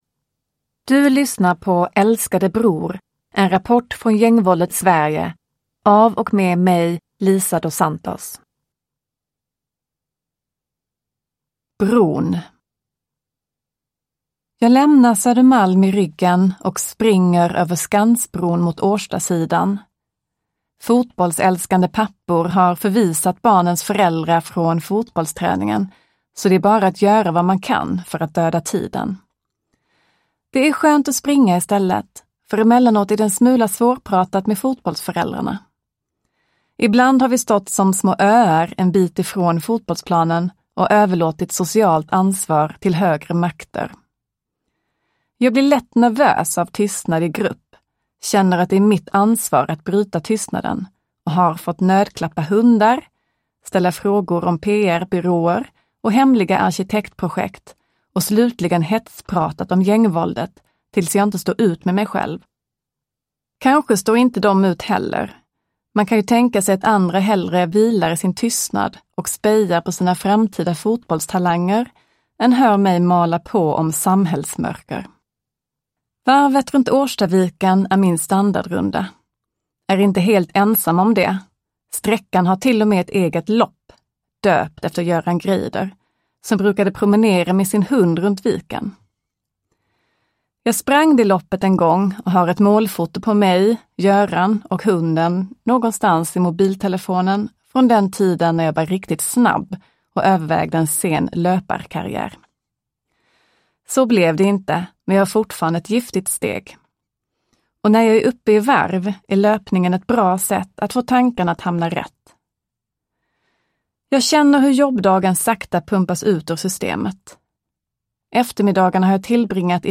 Älskade bror : en rapport från gängvåldets Sverige – Ljudbok – Laddas ner